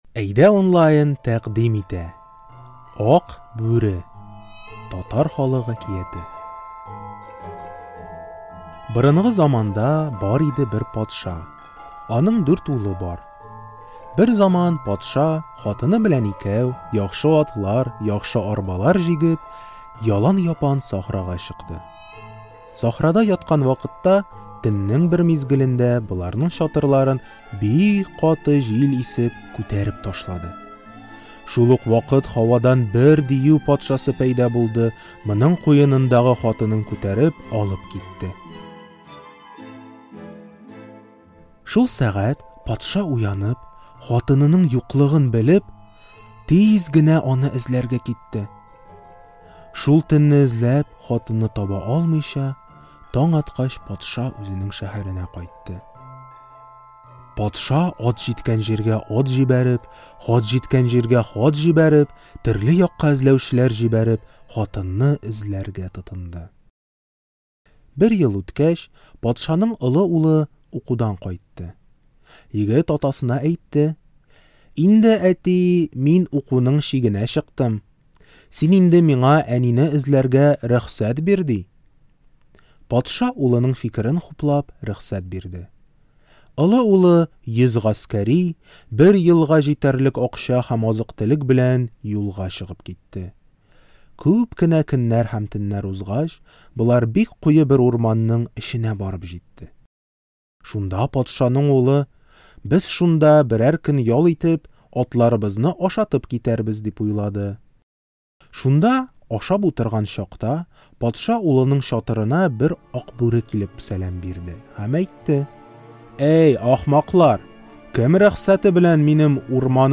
Читаем интересную татарскую сказку "Ак бүре". Текст сказки сокращен для изучающих язык, мы записали к нему аудио, перевели ключевые фразы и слова, подготовили тест по лексике и содержанию.